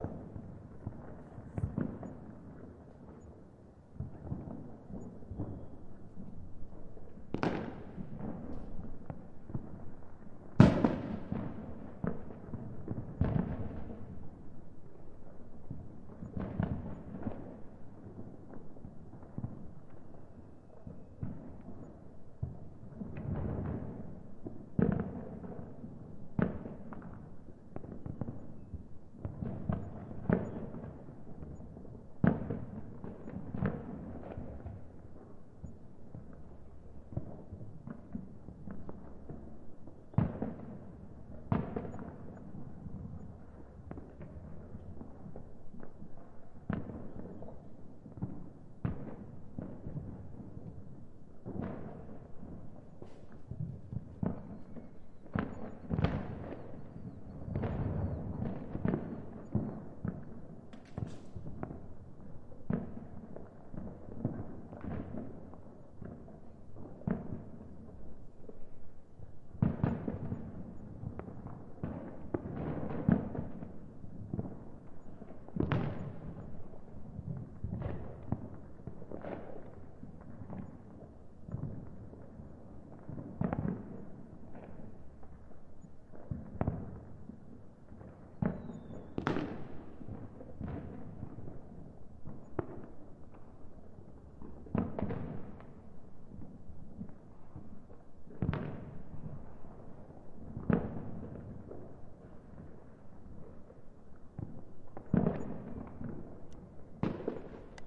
新年鞭炮2
描述：新年鞭炮，氛围记录，第一分钟01/01/2019 MX
Tag: 热潮 爆炸 烟花 爆竹 新年